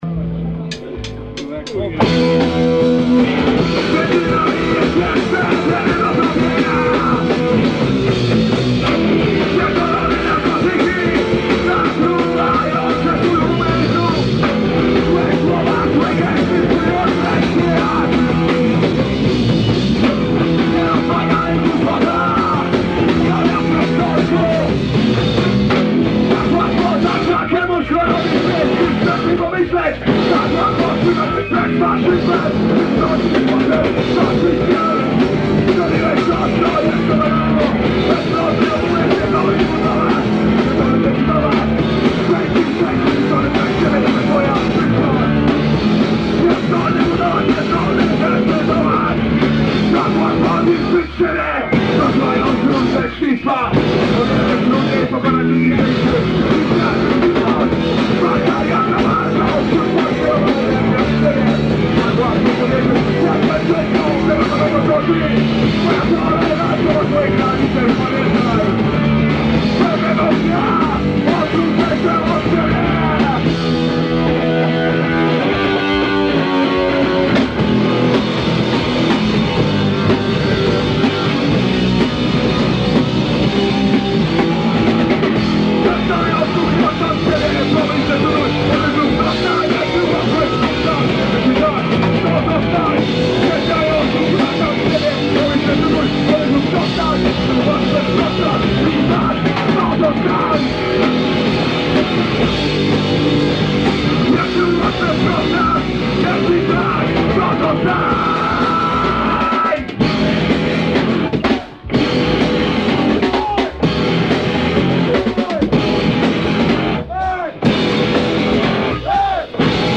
Nagrań dokonano za pomocą decka i 2 mikrofonów.